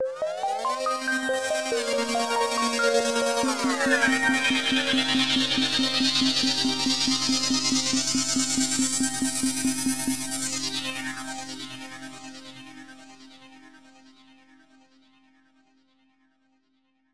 game_over_0.ogg